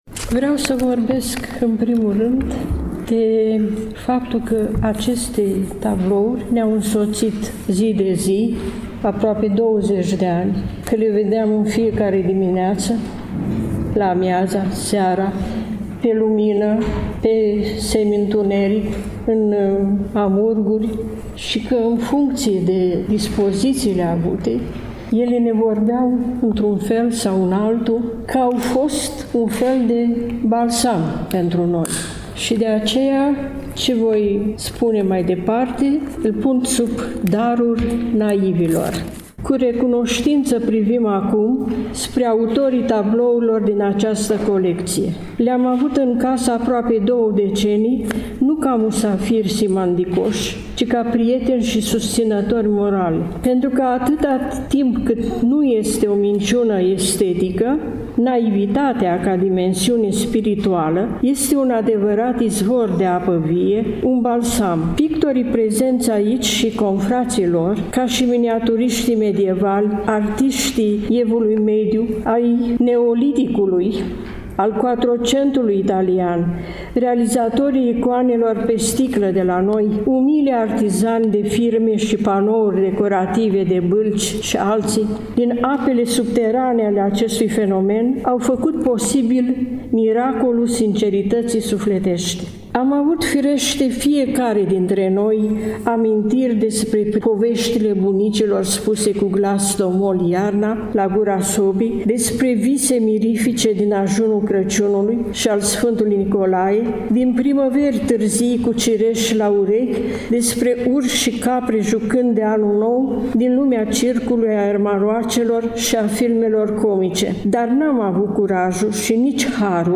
Vă invităm să ascultați, cu atenție, pentru că rar întâlnim oameni care să vorbească despre oameni într-un mod atât de plăcut, de emoționant și de sincer.